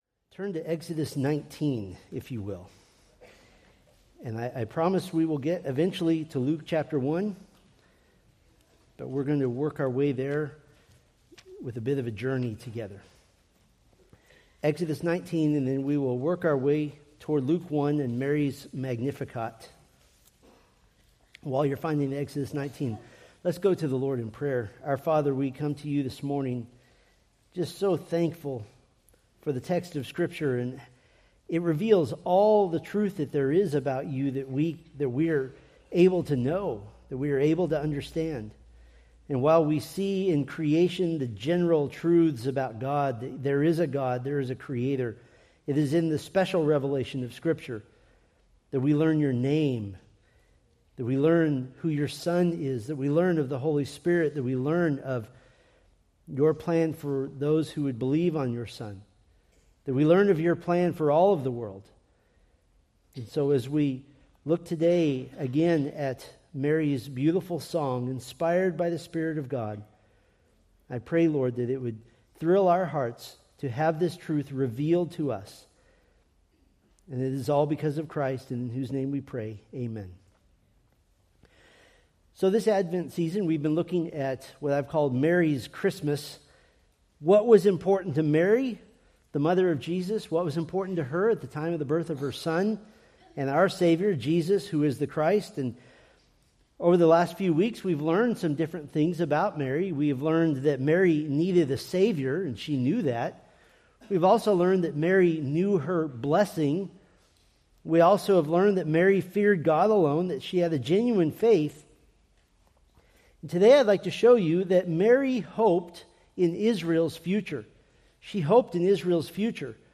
From the Mary's Christmas sermon series.
Sermon Details